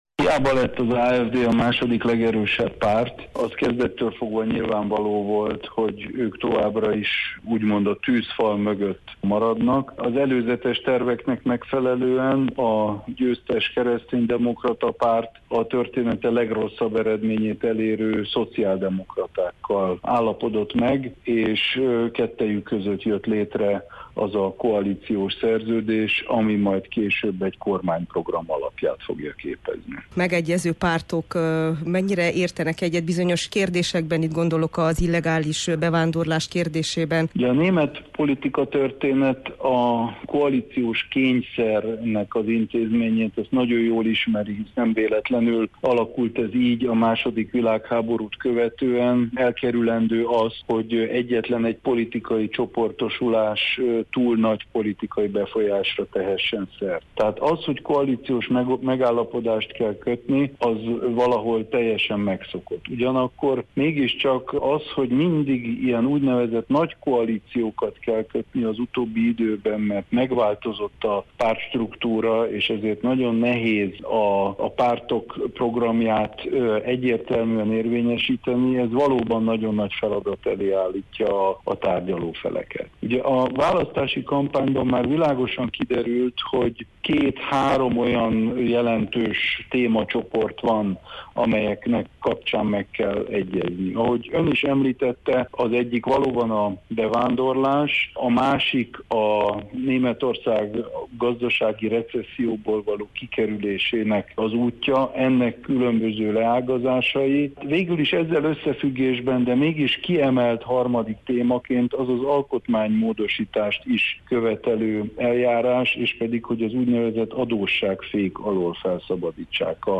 Prőhle Gergely Németorzság szakértőt kérdezte